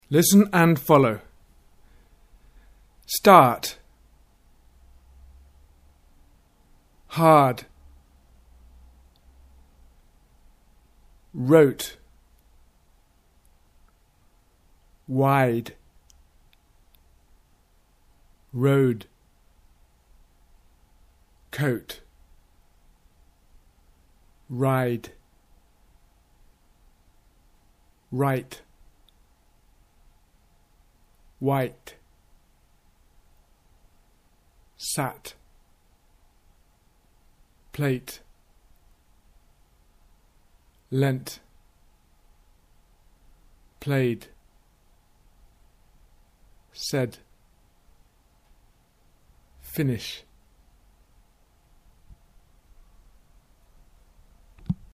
hard v heart - final devoicing
minimal pair discrimination